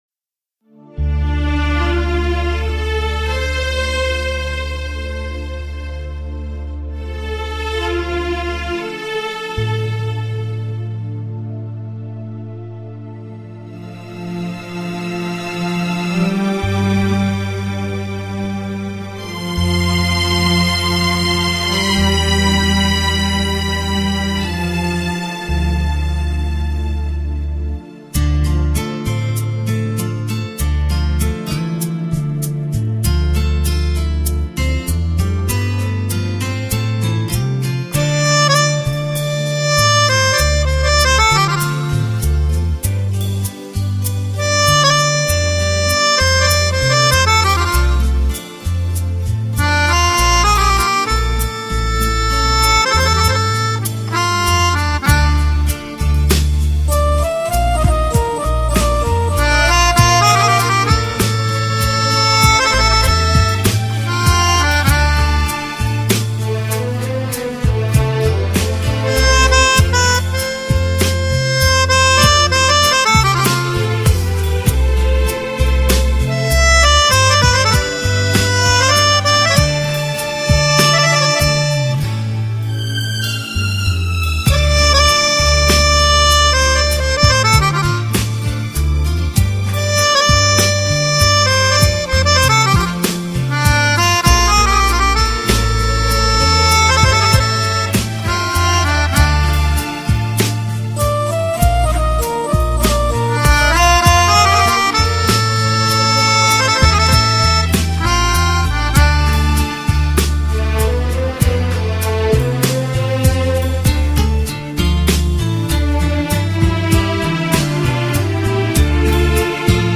HomeMp3 Audio Songs > Instrumental Songs > Old Bollywood